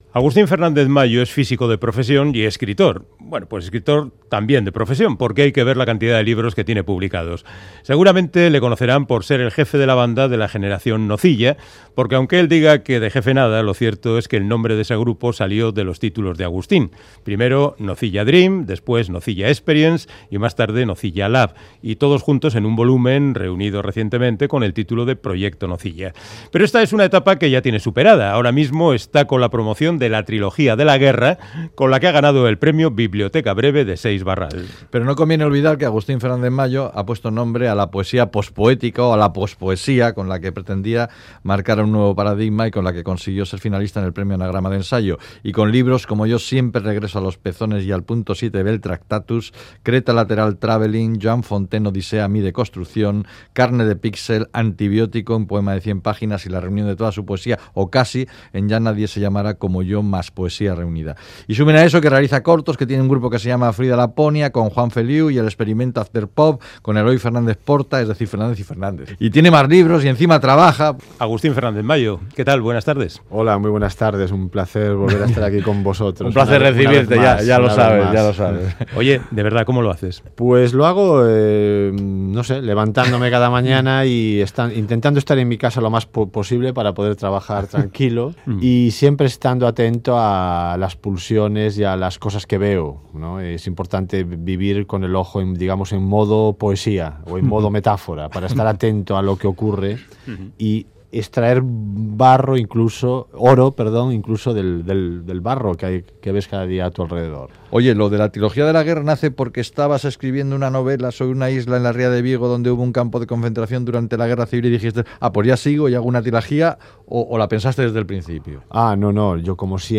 Audio: Charlamos con el escritor gallego Agustín Fernández Mallo sobre su novela Trilogía de la Guerra, con la que ha ganado el premio Biblioteca Breve de la editorial Seix Barral